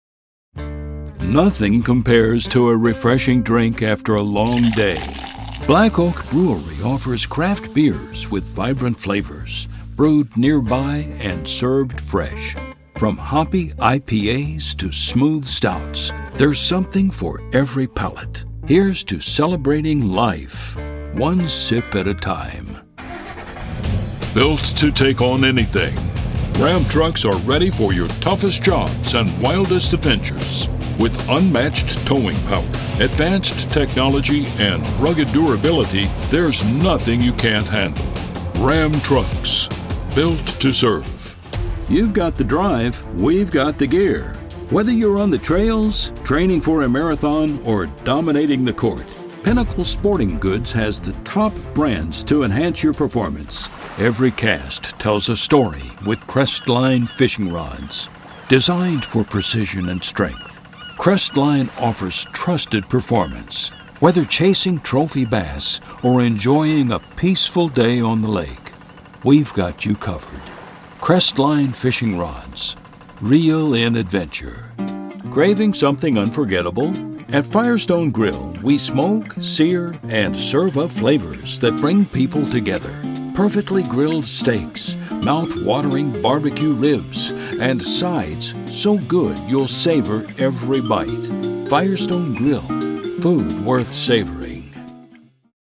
My natural speaking voice can be described as deep, smooth, rich, and resonant. I can speak in a manner that is articulate, informative, and trustworthy. I can be strong and authoritative or warm and caring.